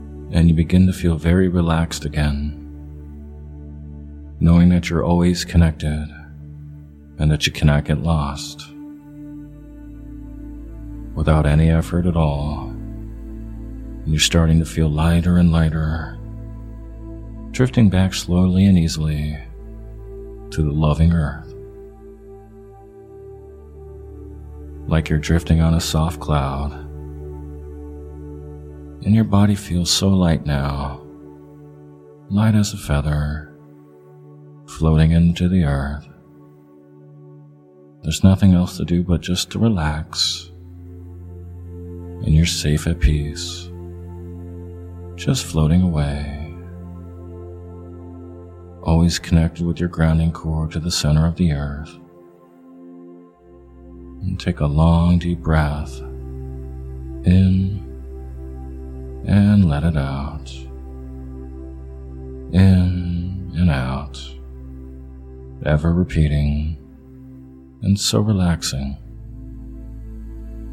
Guided Mediation For Sleep Healing (Body Scan)